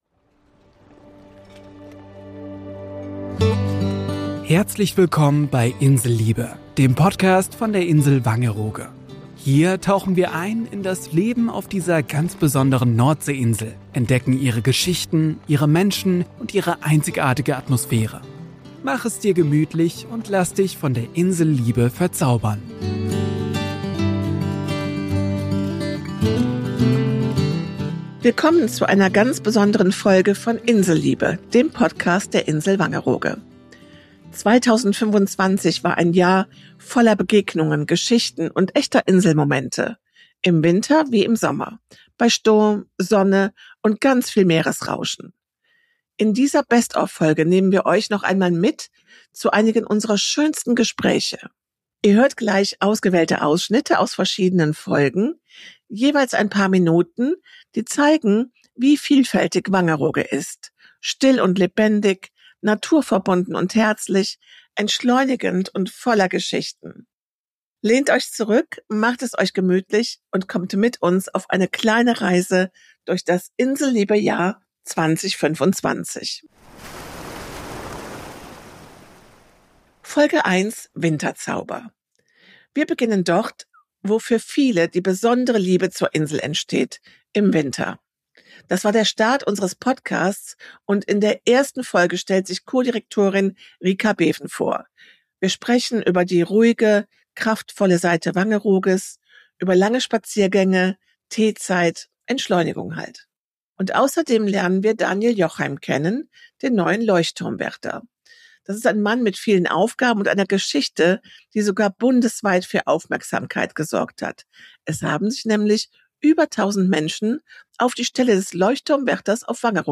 Ob Winterzauber, Sommerfreude, Digital Detox, Inselleben, Musik, Kinderlachen oder Entschleunigung bei der Anreise: Diese Folge zeigt, wie vielfältig Wangerooge ist – zu jeder Jahreszeit. Freut euch auf kurze Originalausschnitte (je 3–5 Minuten) aus den 2025er Folgen.